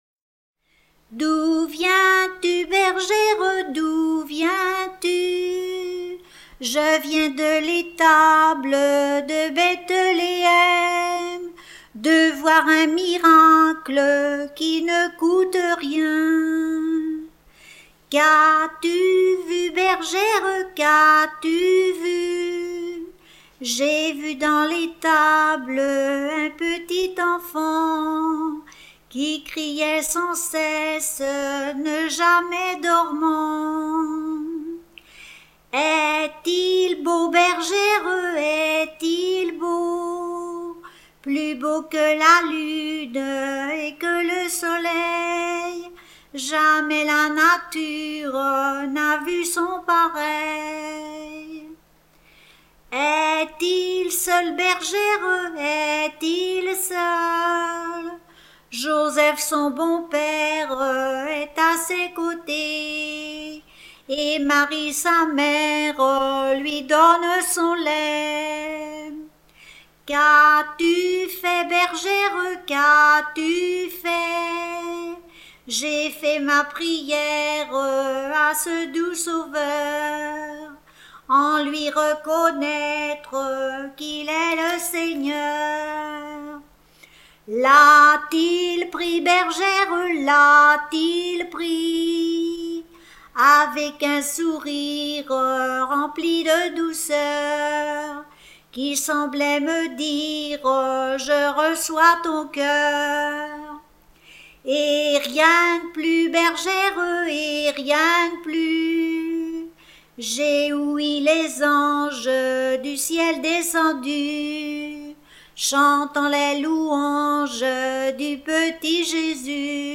Noël
Saint-Aubin-des-Ormeaux
Genre dialogue